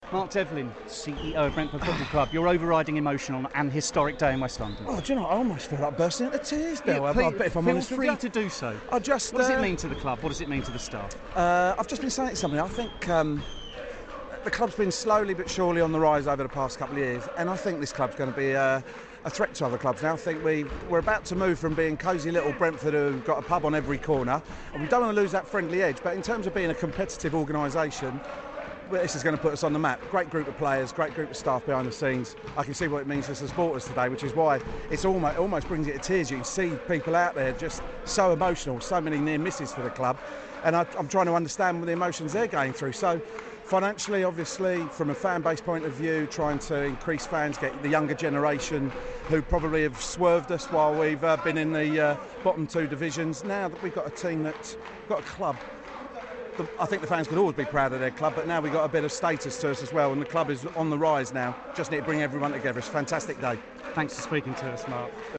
pitch side after achieveing promotion to the Championship